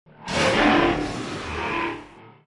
Robot Tiger Roar Bouton sonore